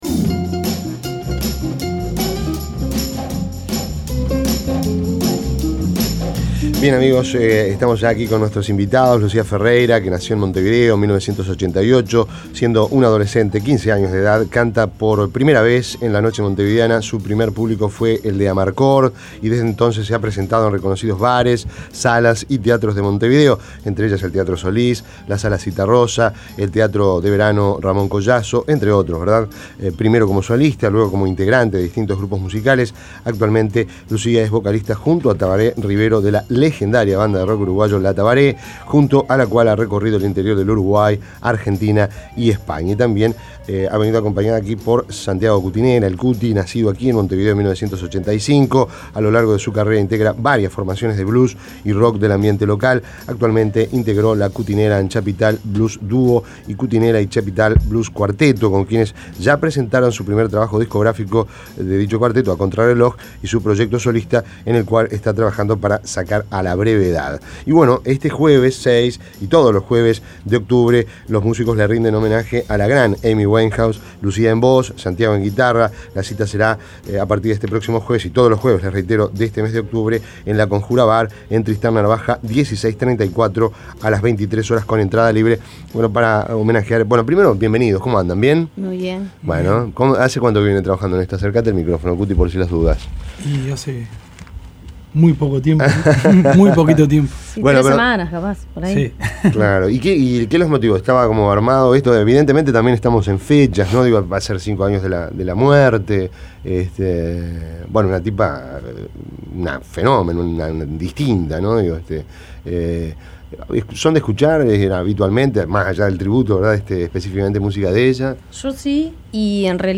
voz
guitarra
Entrevista